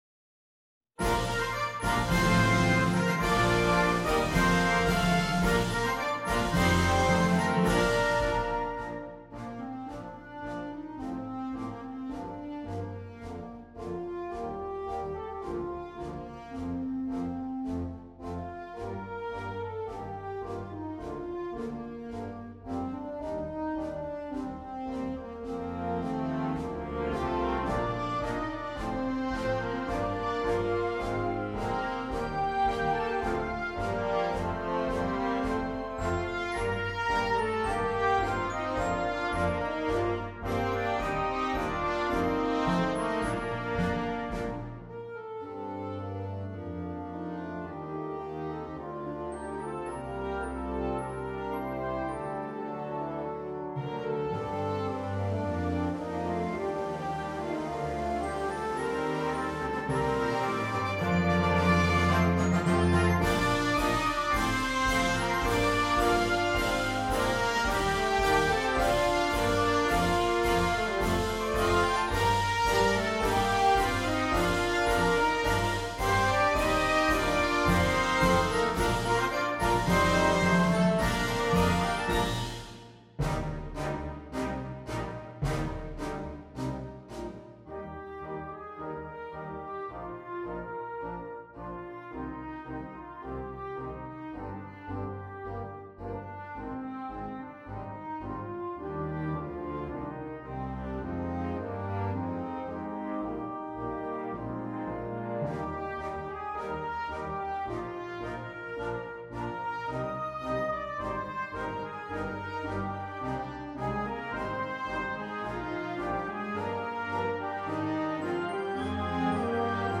Gattung: Konzertmarsch